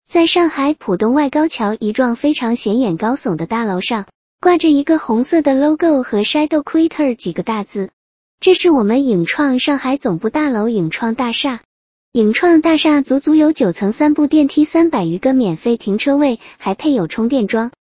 在上海浦东-迅捷文字转语音(2).wav